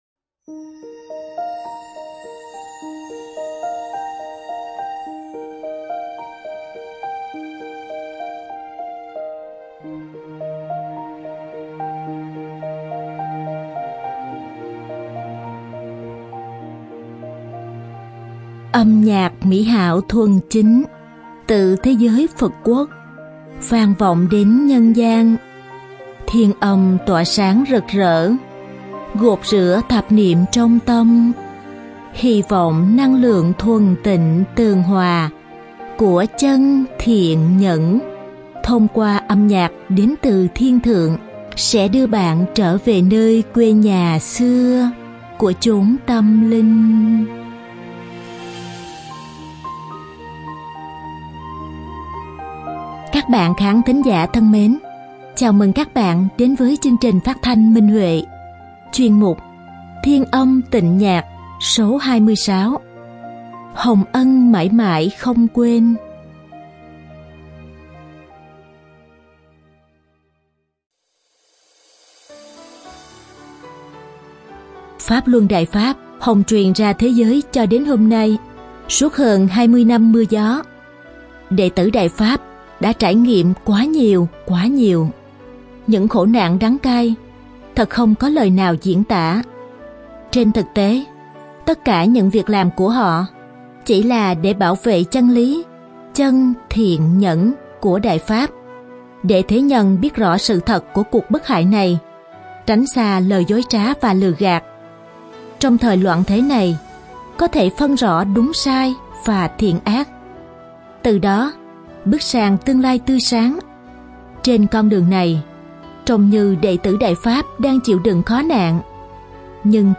Đơn ca nữ
Đơn ca nam
Độc tấu đàn tỳ bà